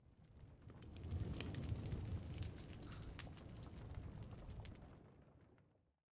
Minecraft Version Minecraft Version 1.21.5 Latest Release | Latest Snapshot 1.21.5 / assets / minecraft / sounds / block / smoker / smoker5.ogg Compare With Compare With Latest Release | Latest Snapshot
smoker5.ogg